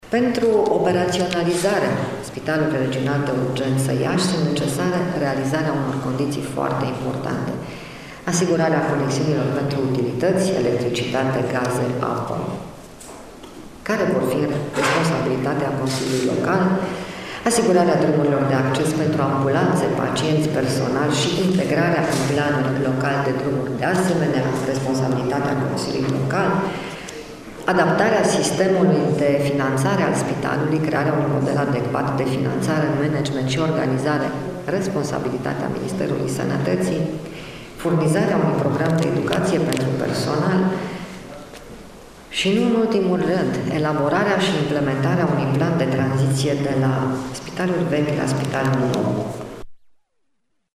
Valoarea investiţiei a fost anunţată astăzi, la Iaşi, de ministrul Sănătăţii Sorina Pintea în cadrul dialogurilor cu reprezentanţii Uniunii Europene.
Ministrul Sorina Pintea s-a referit şi la măsurile ce trebuie luate pentru operaţionalizarea Unităţii spitaliceşti: